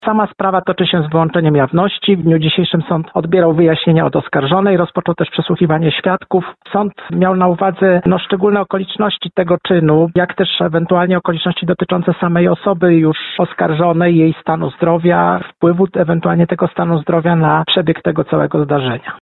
– Zwłoki noworodka znaleziono w reklamówce – mówi sędzia Sądu Okręgowego w Lublinie, Andrzej Mikołajewski.